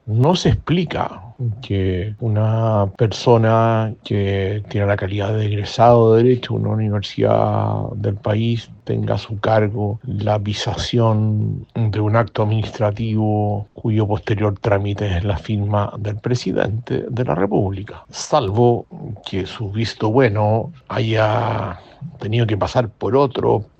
El exministro del Interior, Jorge Burgos, aseguró que “no se explica” lo sucedido.